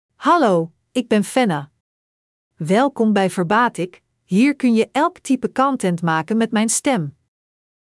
Fenna — Female Dutch AI voice
Fenna is a female AI voice for Dutch (Netherlands).
Voice sample
Female
Fenna delivers clear pronunciation with authentic Netherlands Dutch intonation, making your content sound professionally produced.